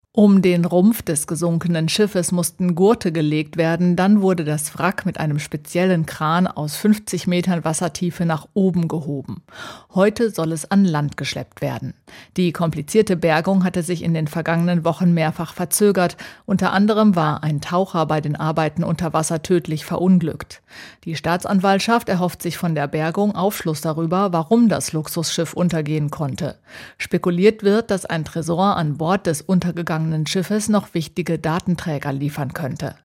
Italien-Korrespondentin